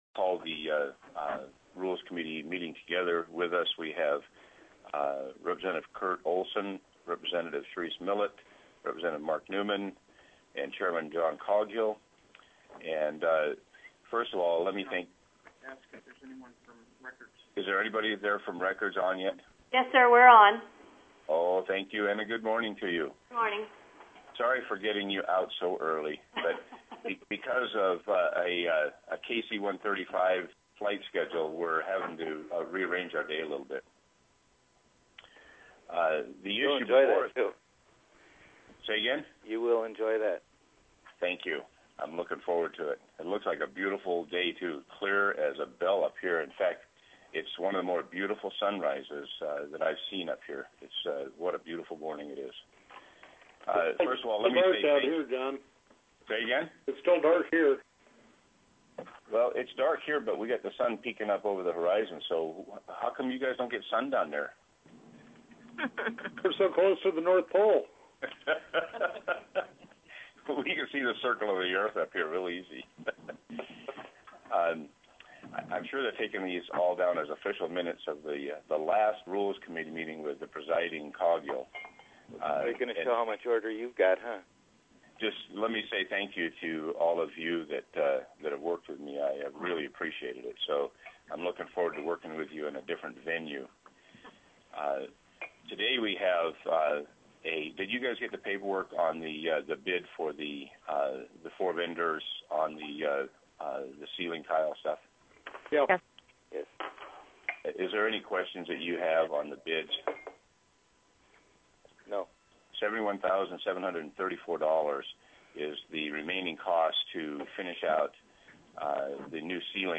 + teleconferenced
Representative Charisse Millett (via teleconference)